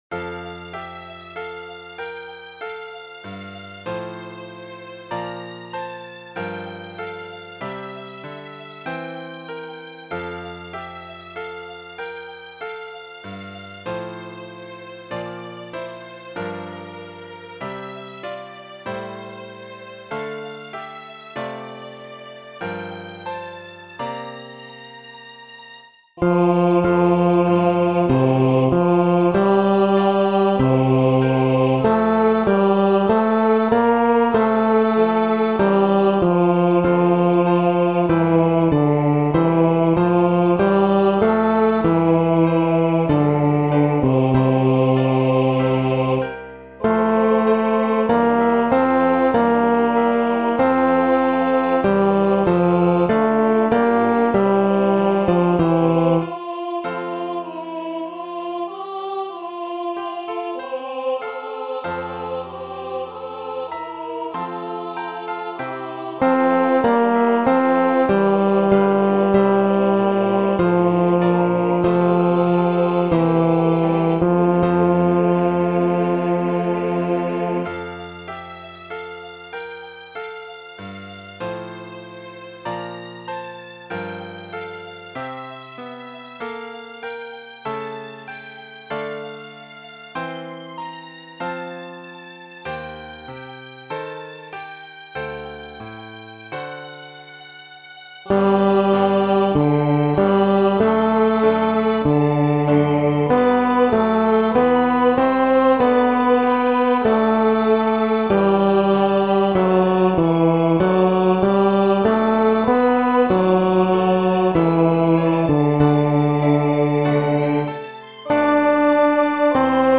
テノール（フレットレスバス音）